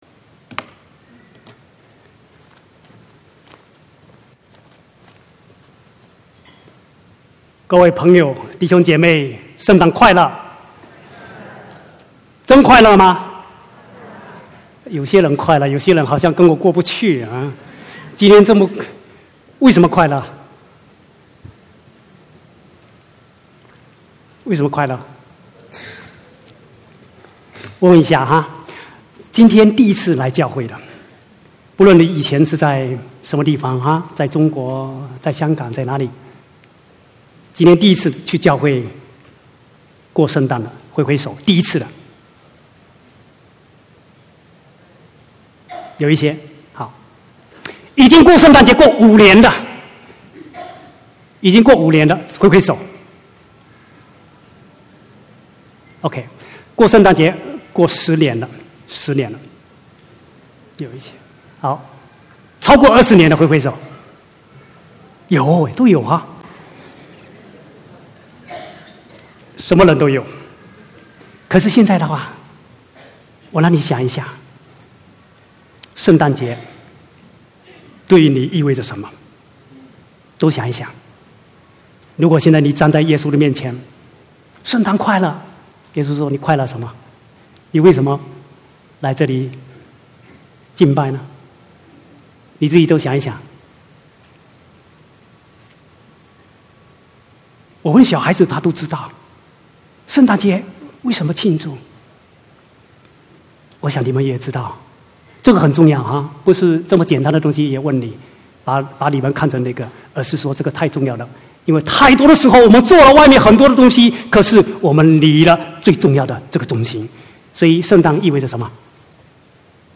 24/12/2017 國語堂講道